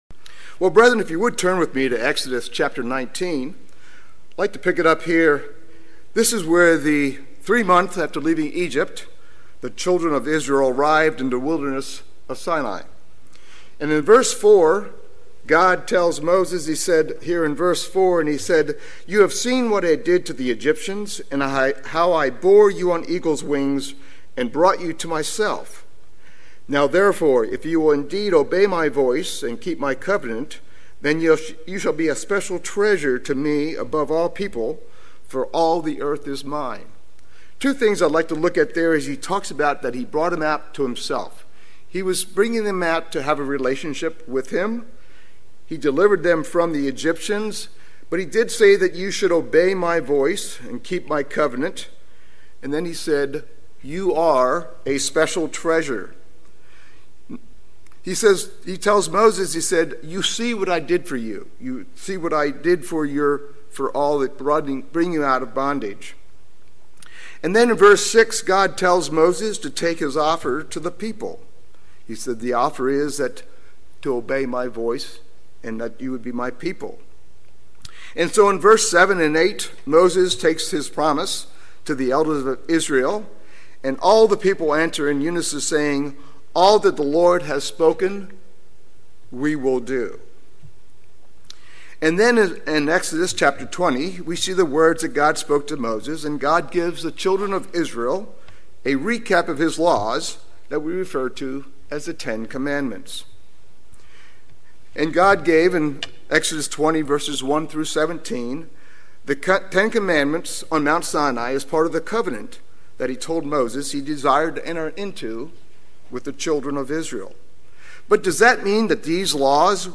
Sermons
Given in Atlanta, GA